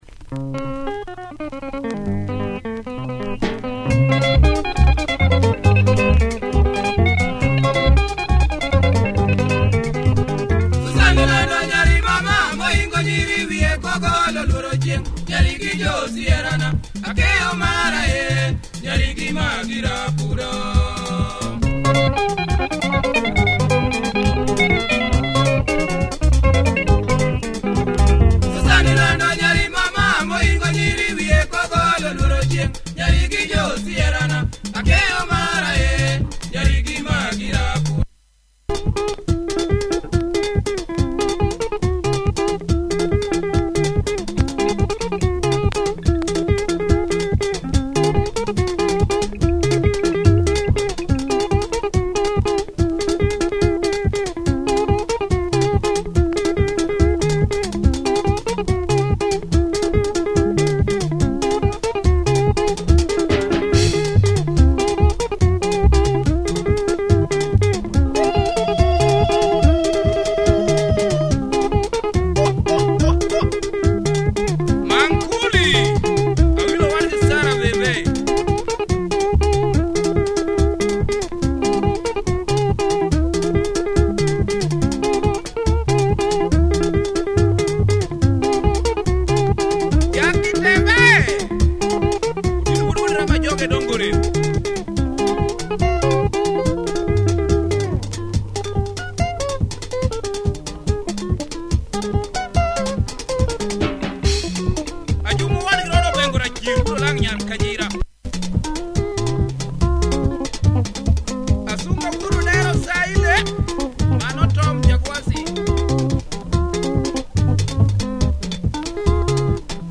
Killer Luo benga, heavy breakdown !